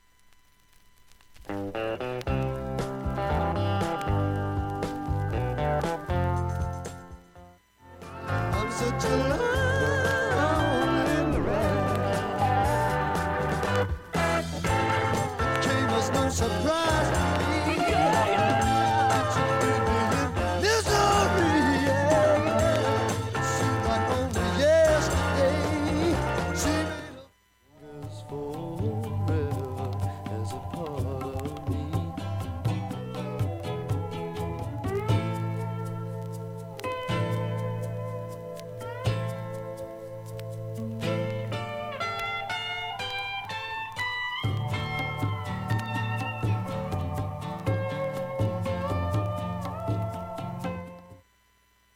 B-1にかすかなチリと
中盤に６回かすかなプツ出ます。